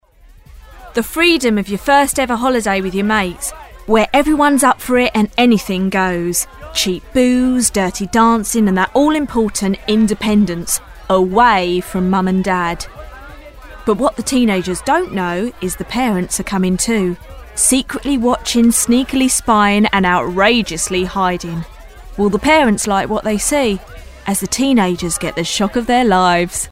• Native Accent: London
• Home Studio